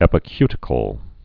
(ĕpĭ-kytĭ-kəl)